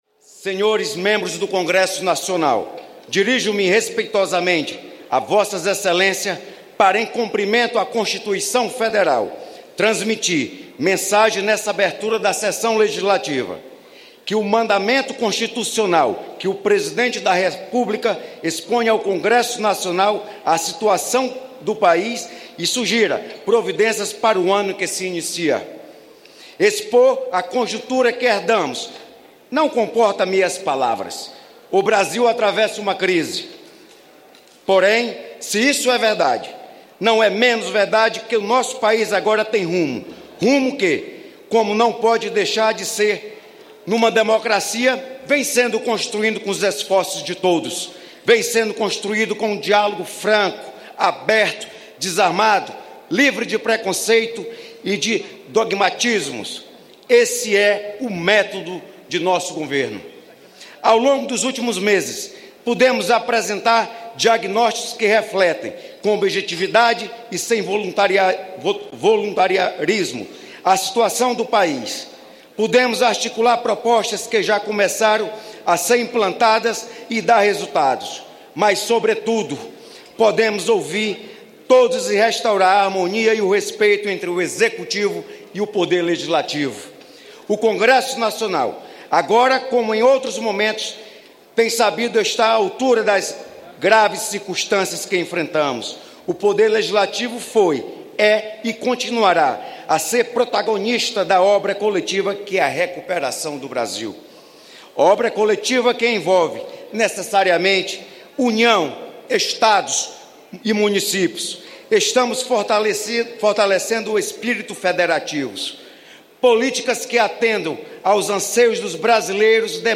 Leitura da mensagem do presidente Michel Temer
Discurso do presidente do Congresso, senador Eunício Oliveira